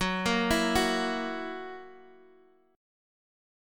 F#7 chord